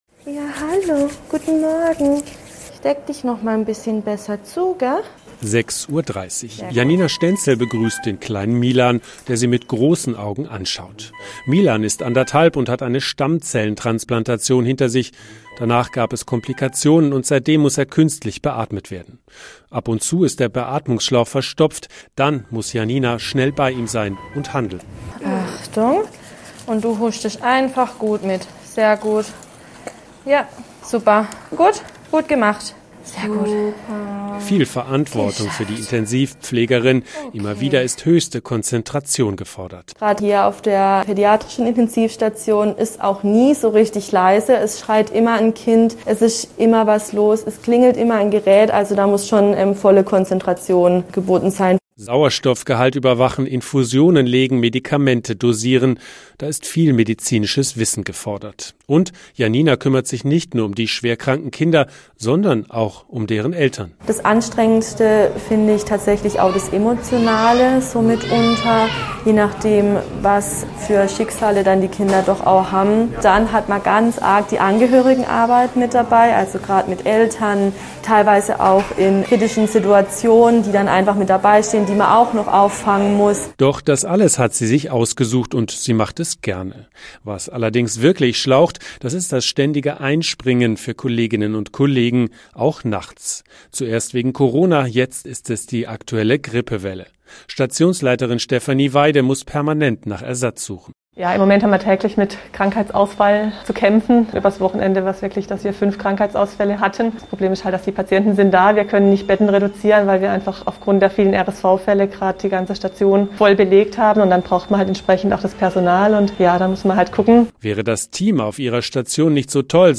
Radioreportage